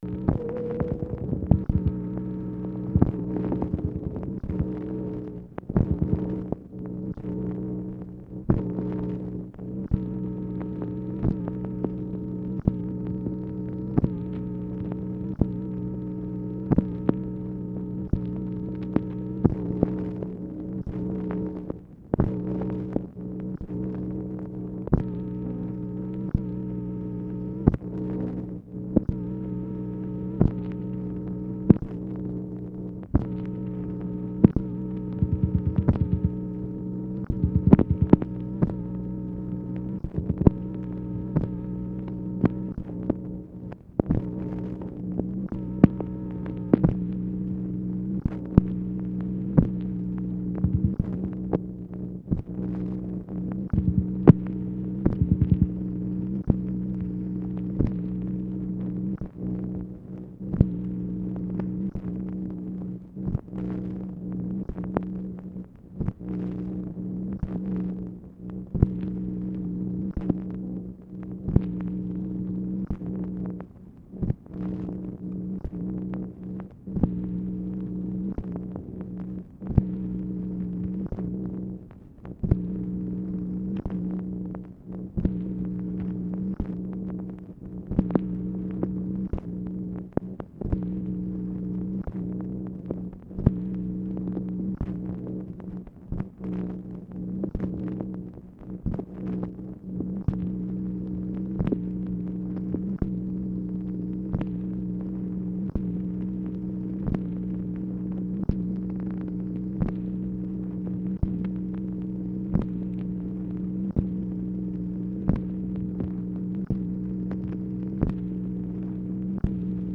MACHINE NOISE, January 31, 1964
Secret White House Tapes